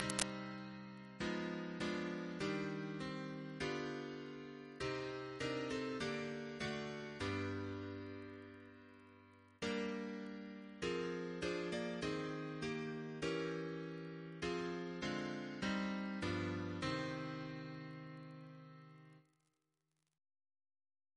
Double chant in C minor Composer: Walter Ernest Smith Reference psalters: ACB: 369